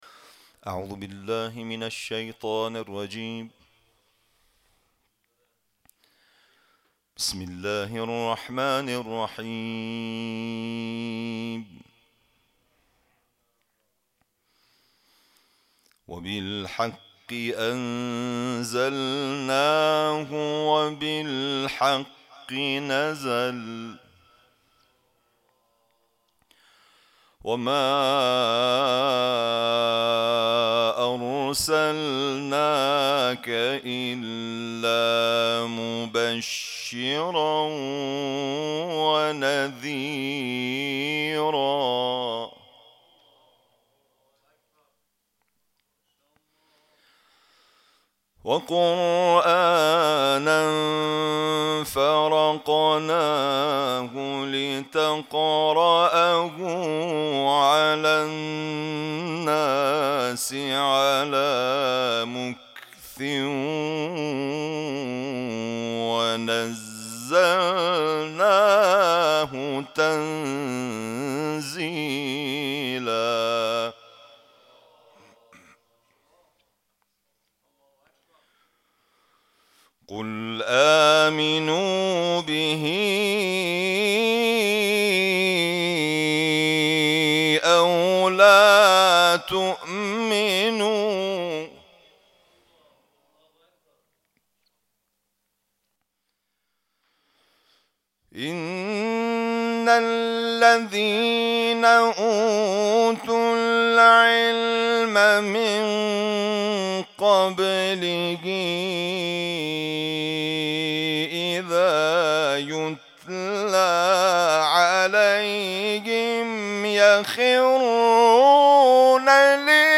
با حضور در دارالقرآن نفحات به تلاوت آیات 105 الی 111 سوره اسراء پرداخت.